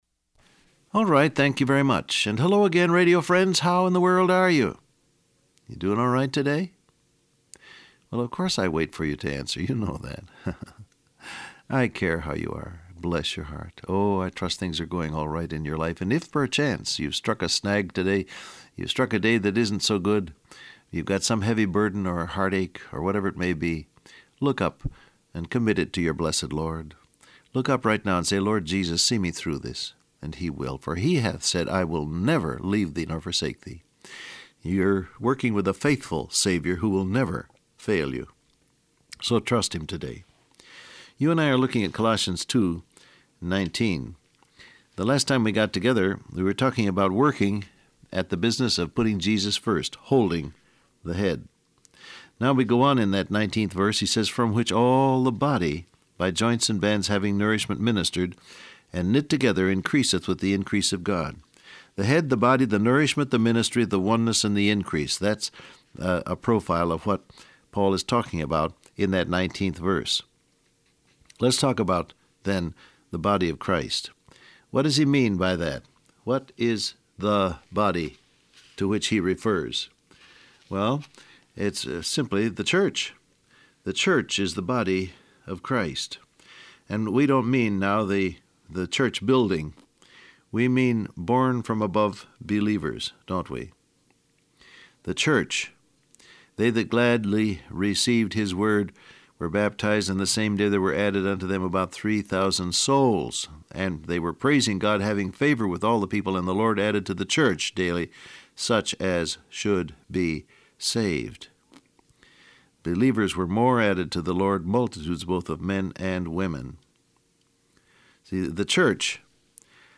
Download Audio Print Broadcast #1895 Scripture: Colossians 2:19 , 1 Corinthians 12 Transcript Facebook Twitter WhatsApp Alright, thank you very much, and hello again radio friends, how in the world are you?